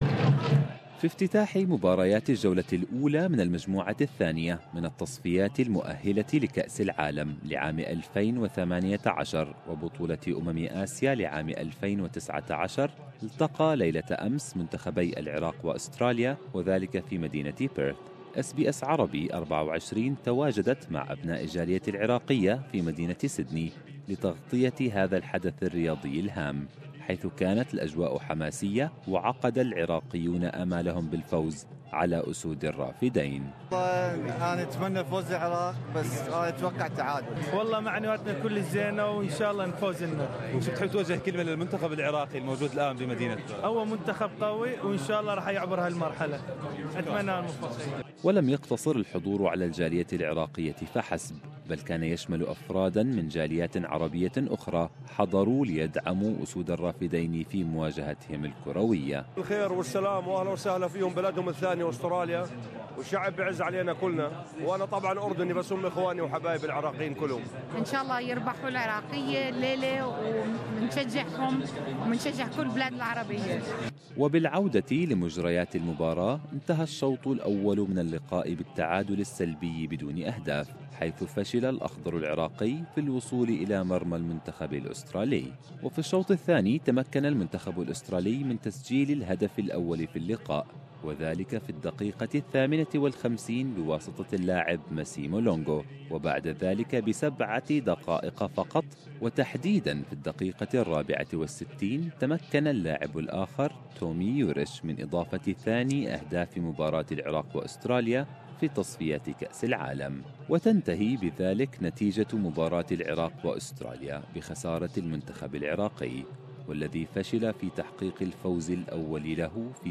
اس بي اس عربي 24 تواجدت مع أبناء الجالية العراقية في مدينة سيدني لتغطية الحدث الرياضي وكانت الأجواء حماسية وعقد العراقيون آمالهم بالفوز على أسود الرافدين.